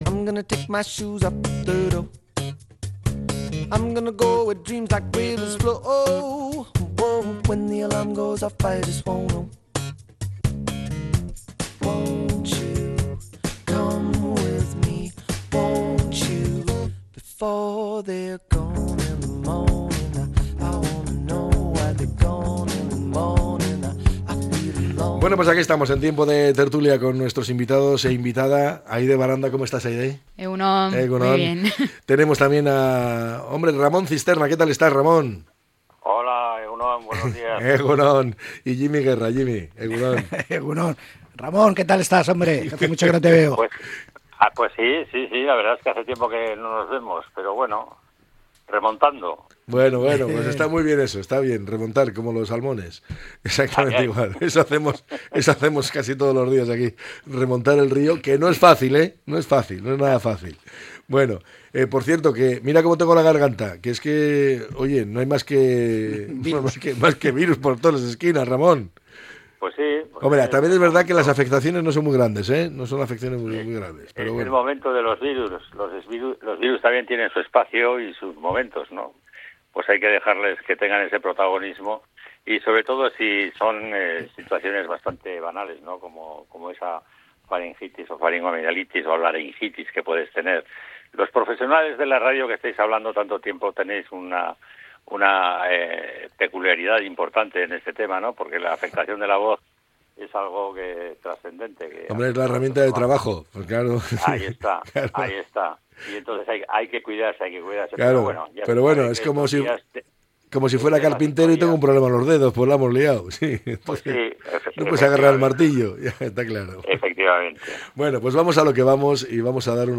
La tertulia 27-02-25. Escucha el podcast Reforma fiscal de Euskadi en Radio Popular.